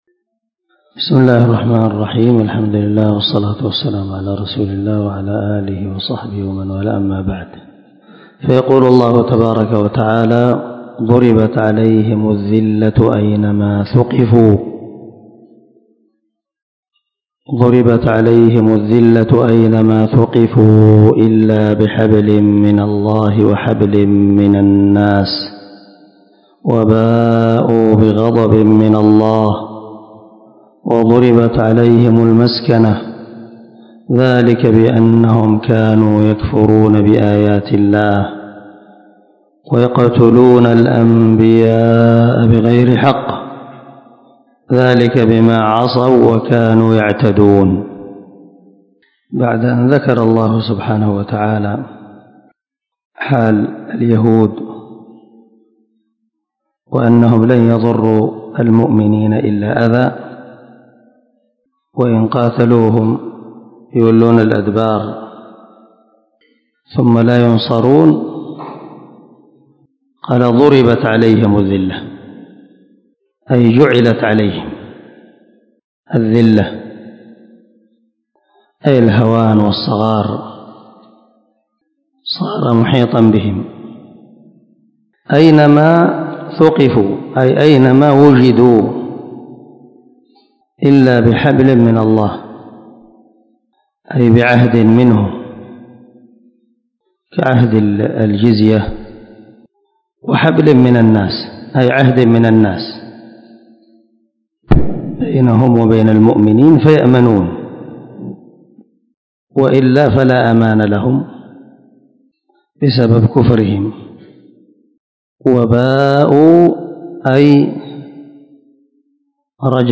190الدرس 35 تفسير آية ( 112 ) من سورة آل عمران من تفسير القران الكريم مع قراءة لتفسير السعدي